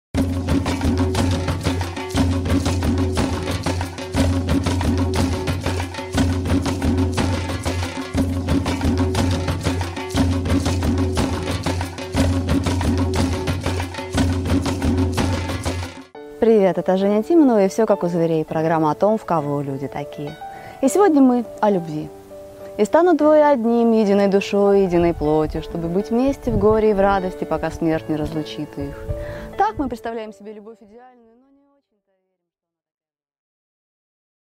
Аудиокнига Шистосомы и удильщики: люблю тебя, паразита!